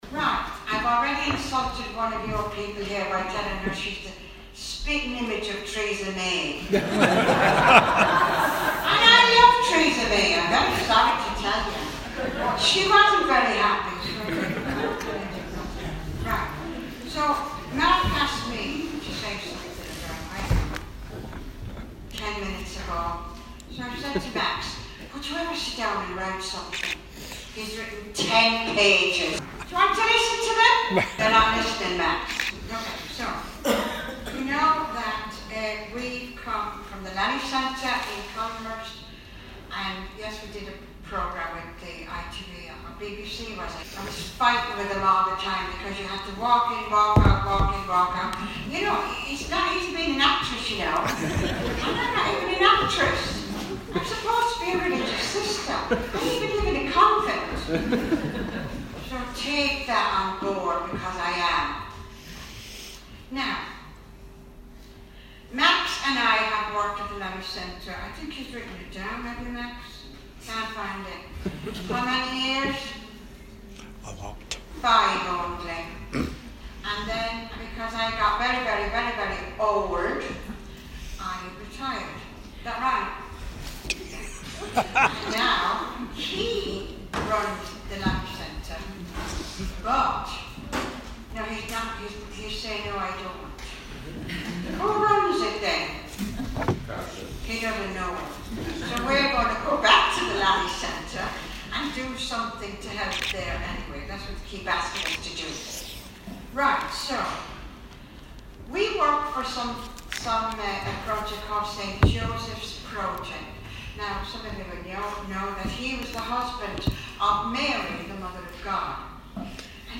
Rev Susan Brown talk,
Audience gathering to hear the talks